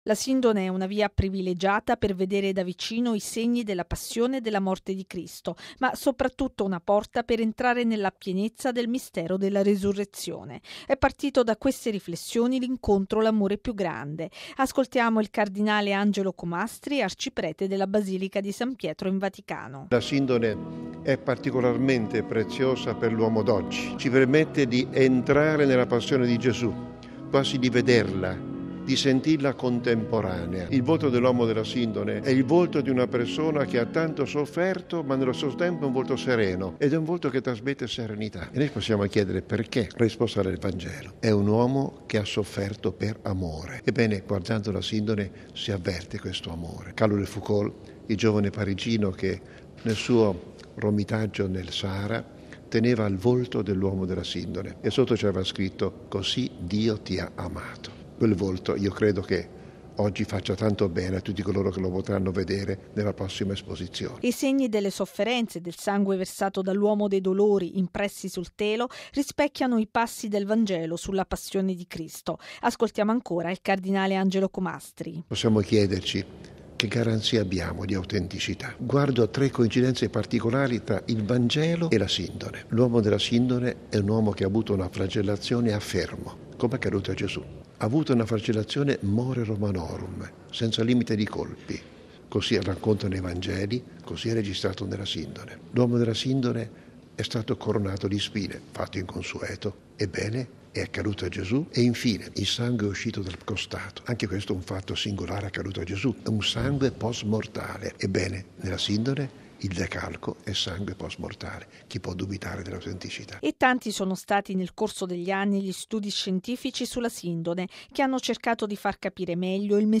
“L’amore più grande” è il tema dell’incontro sulla Sindone che si è svolto ieri sera a Roma nella Basilica di Santa Croce in Gerusalemme, promosso dall’Ufficio diocesano per la pastorale universitaria e dall’Opera romana Pellegrinaggi.